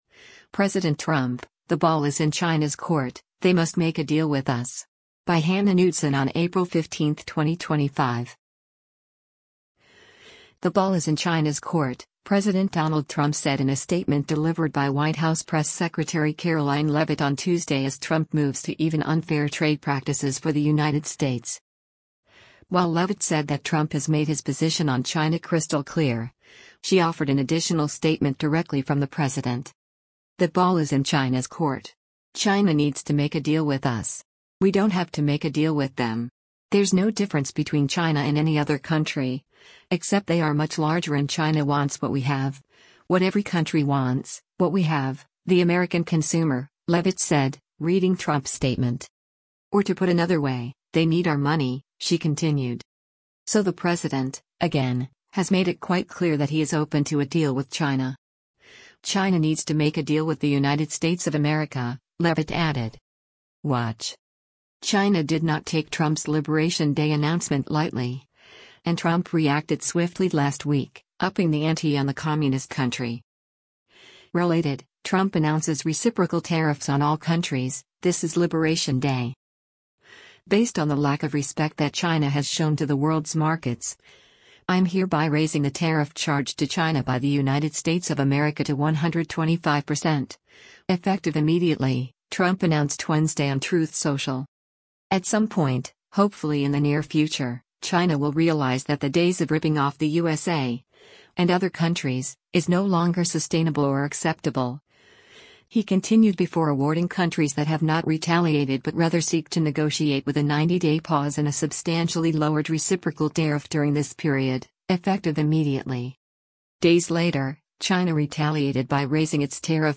“The ball is in China’s court,” President Donald Trump said in a statement delivered by White House Press Secretary Karoline Leavitt on Tuesday as Trump moves to even unfair trade practices for the United States.